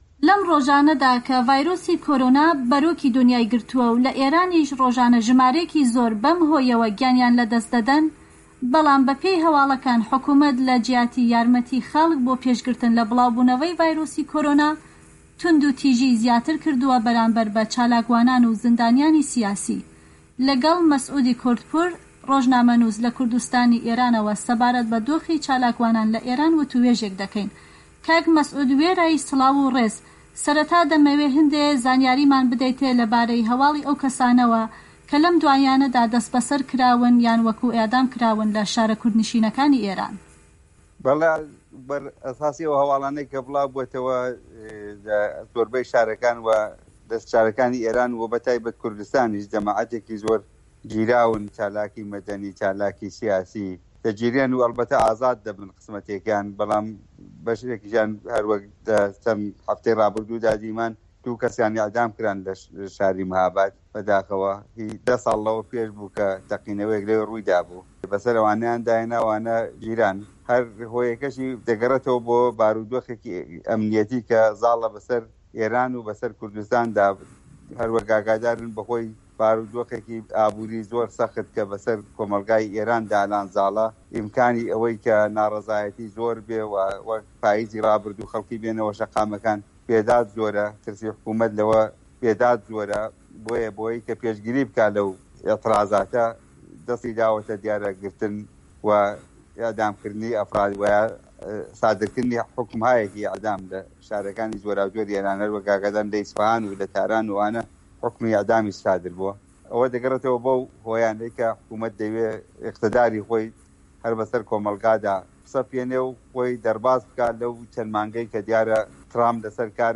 ئێران - گفتوگۆکان